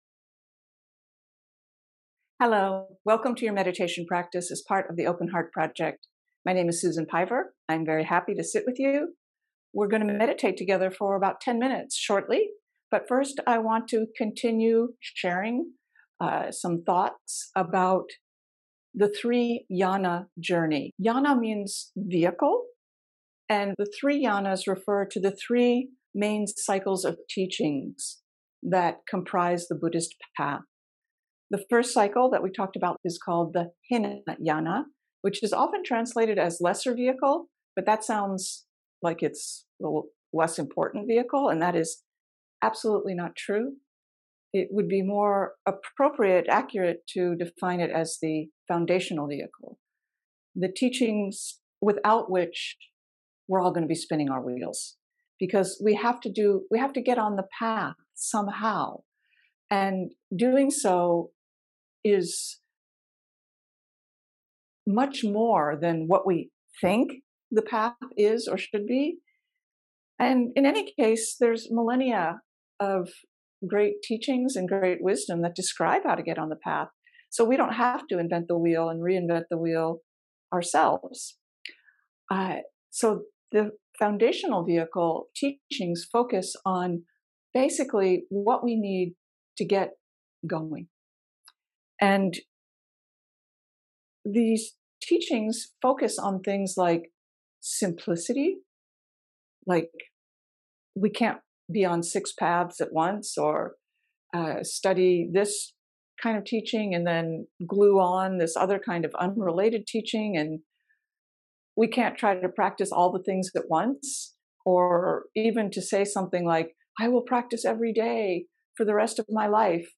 Meditation practice begins at 26:21.